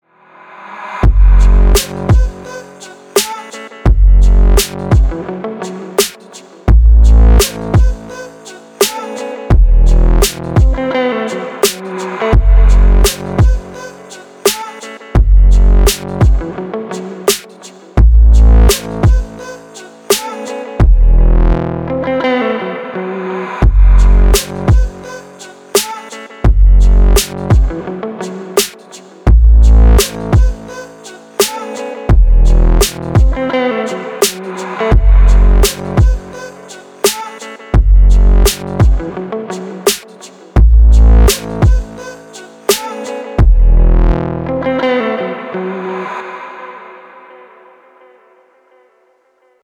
• Качество: 320, Stereo
веселые
спокойные
чувственные
электрогитара
alternative
Шикарная игра на электрогитаре